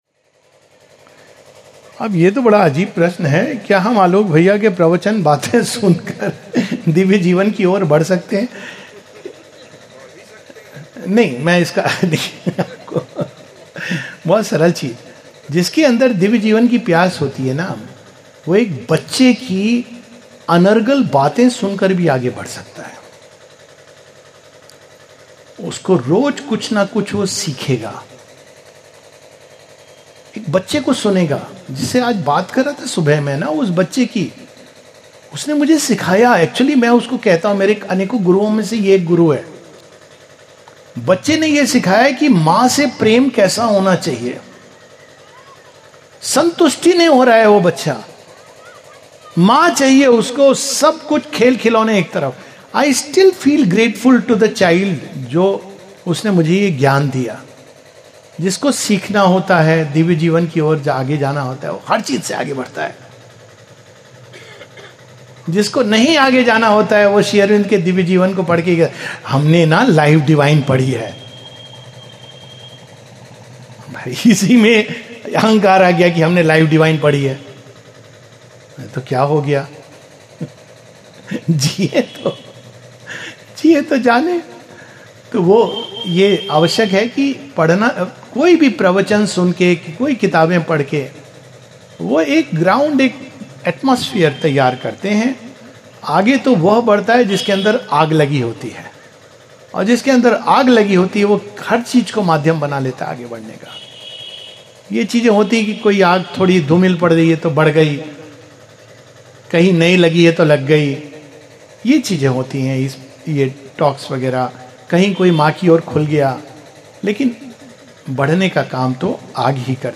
[Towards the Divine Life]. A fragment of the talk at Sri Aurobindo Gram, Mehsua, MP